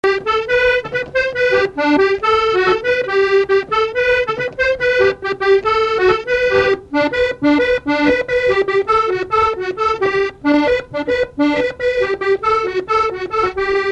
Chants brefs - A danser
danse : polka
Pièce musicale inédite